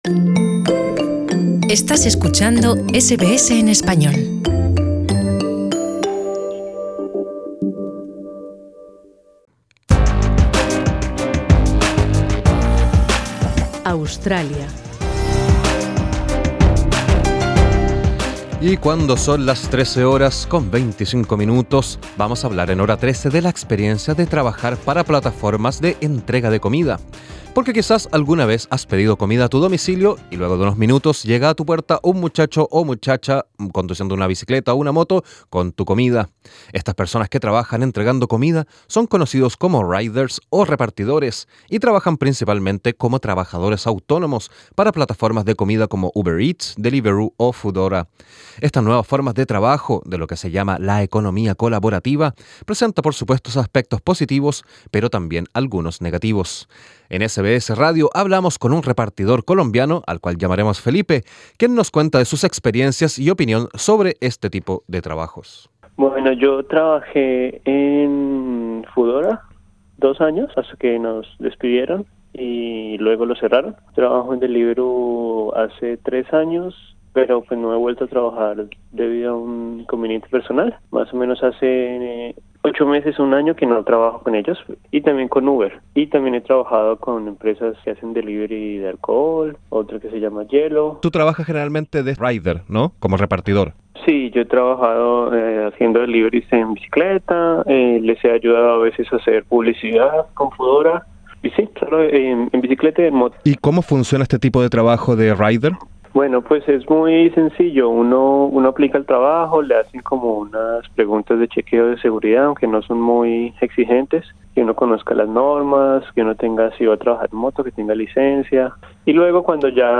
En Radio SBS hablamos con un rider o repartidor, nacido en Colombia, quien nos cuenta de sus experiencias y su opinión sobre este tipo de trabajos.